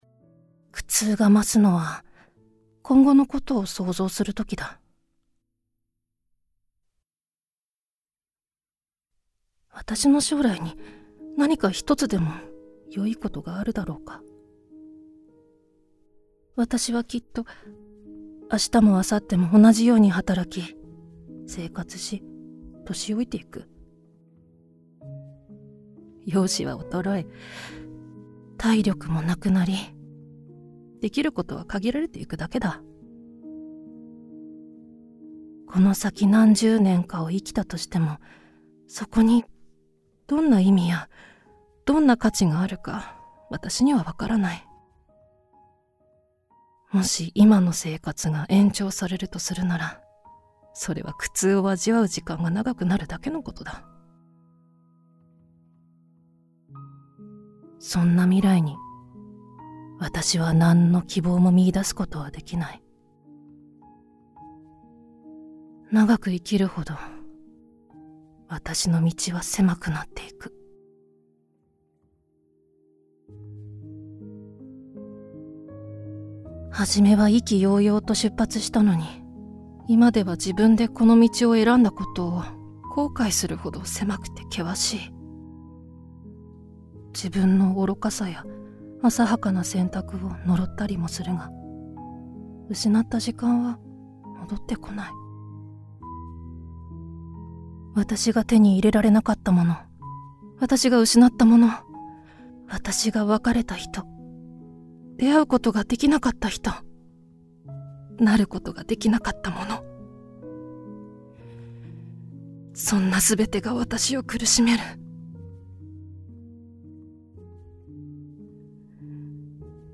[オーディオブック] 生きていても苦しいだけ